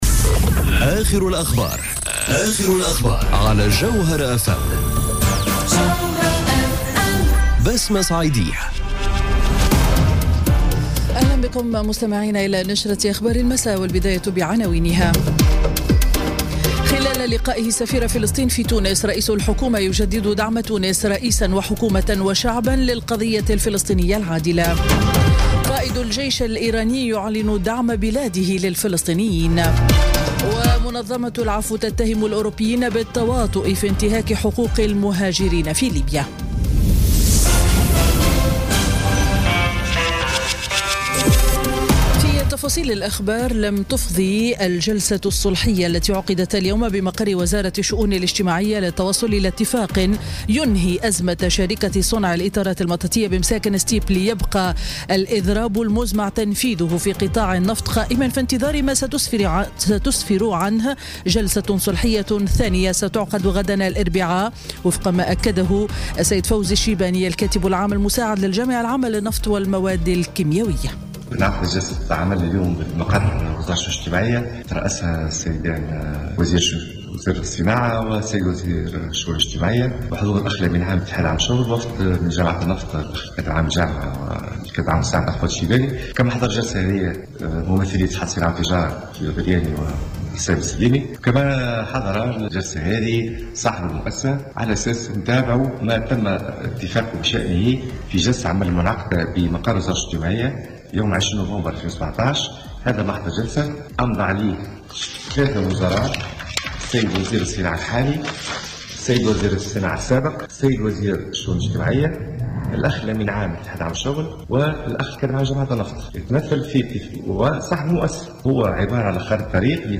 نشرة الأخبار السابعة مساءً ليوم الثلاثاء 12 ديسمبر 2017